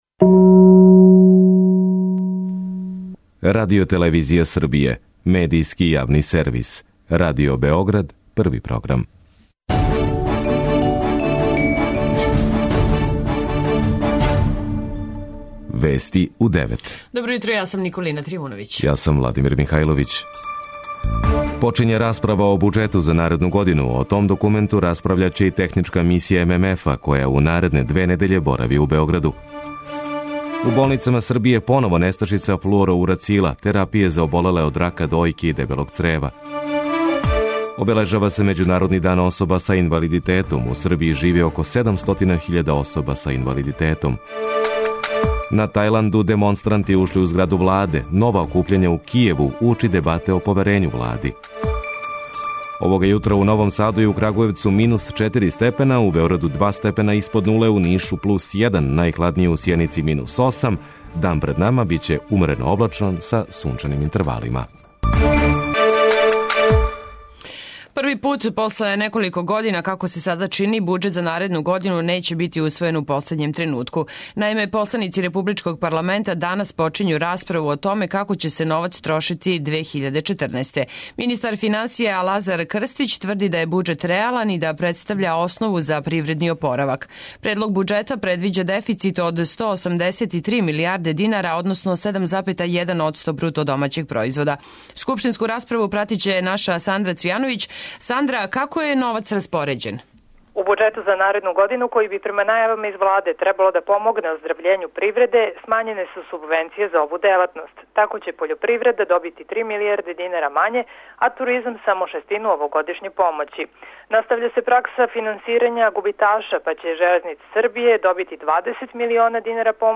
преузми : 10.45 MB Вести у 9 Autor: разни аутори Преглед најважнијиx информација из земље из света.